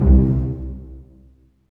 Index of /90_sSampleCDs/Roland LCDP13 String Sections/STR_Vcs Marc&Piz/STR_Vcs Pz.2 amb
STR PIZZ.00L.wav